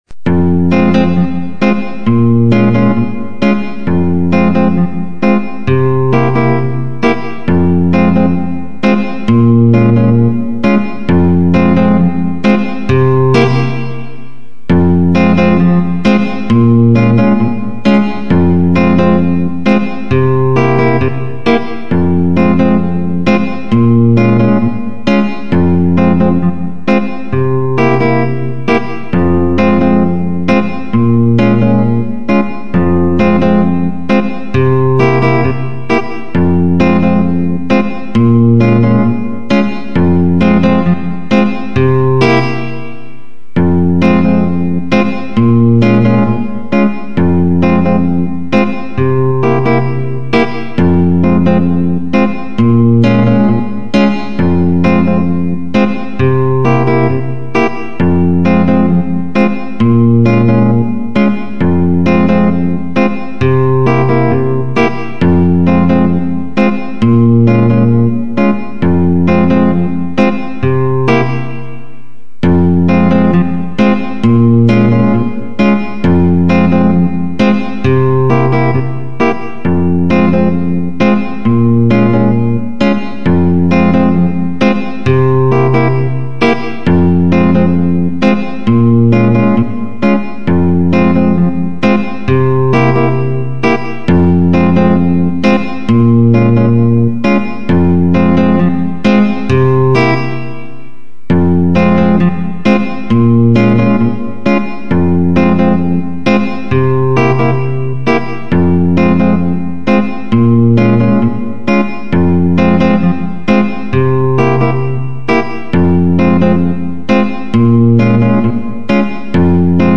Качество:Студия